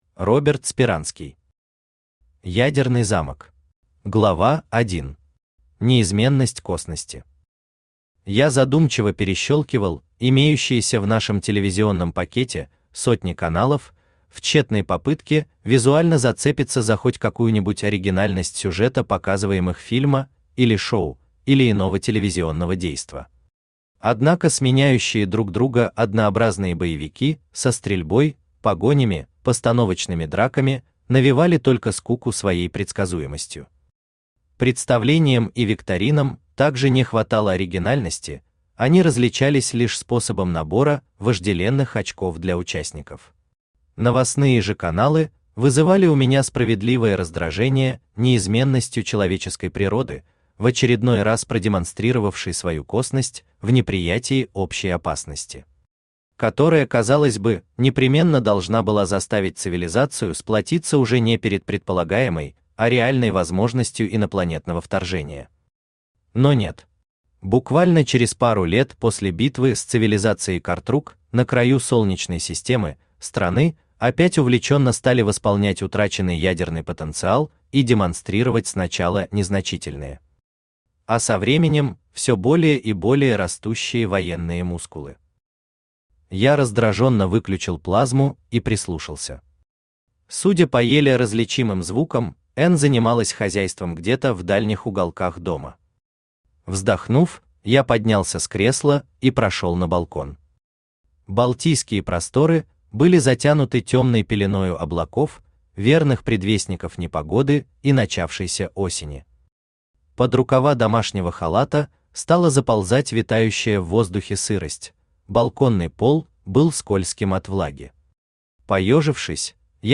Аудиокнига Ядерный замок | Библиотека аудиокниг
Aудиокнига Ядерный замок Автор Роберт Юрьевич Сперанский Читает аудиокнигу Авточтец ЛитРес.